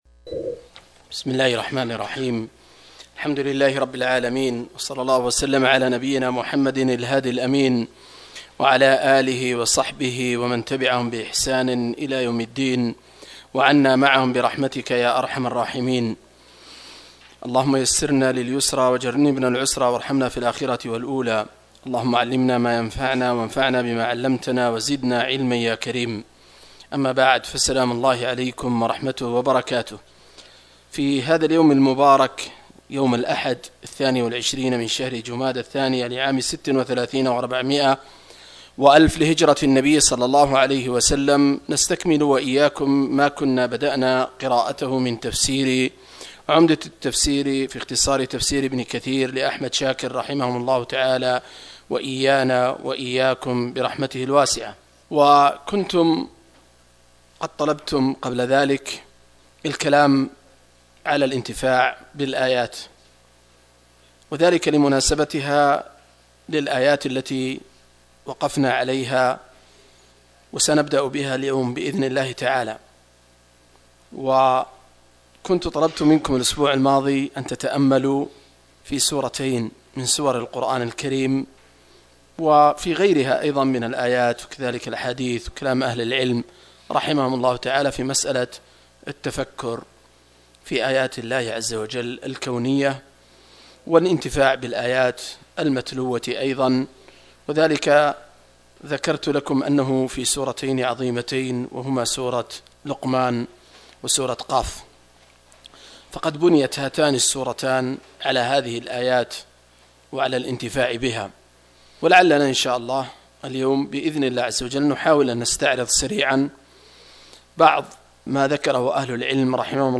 079- عمدة التفسير عن الحافظ ابن كثير رحمه الله للعلامة أحمد شاكر رحمه الله – قراءة وتعليق –